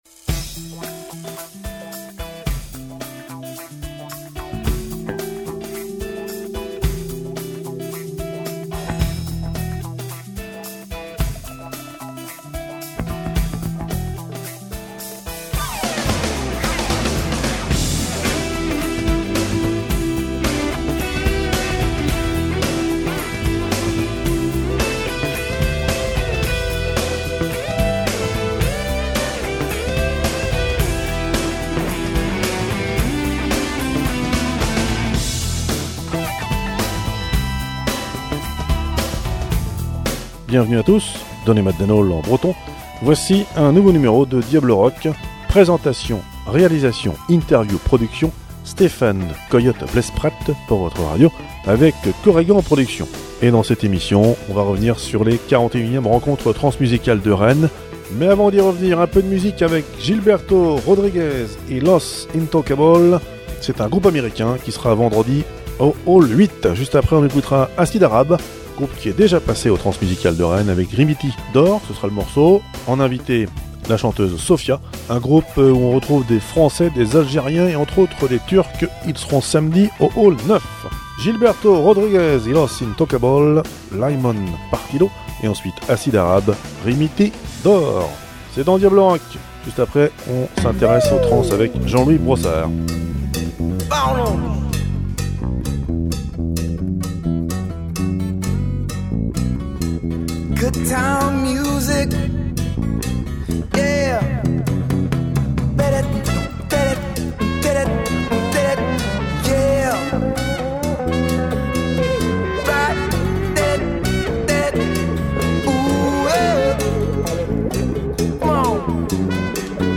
Emissions DiabloRock – Spéciales Transmusicales de Rennes (Musique + Interview de présentation